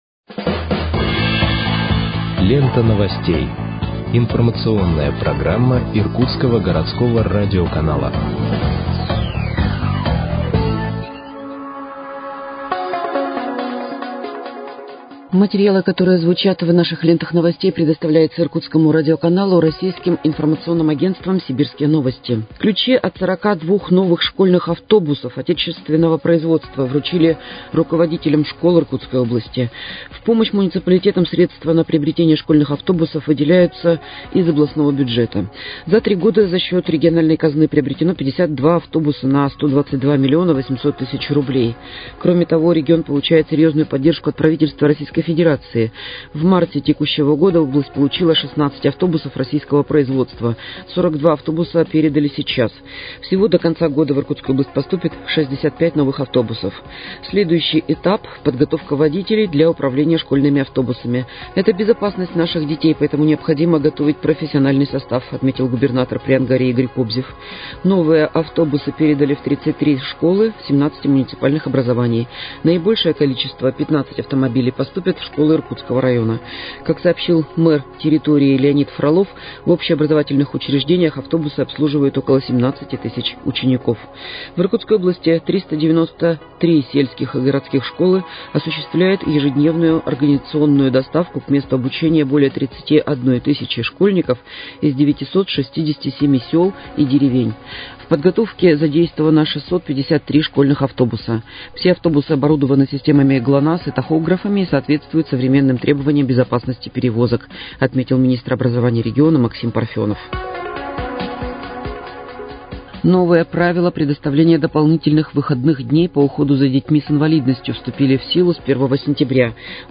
Выпуск новостей в подкастах газеты «Иркутск» от 06.09.2023 № 2